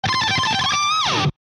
Sound Buttons: Sound Buttons View : Electric Guitar Shrednanigans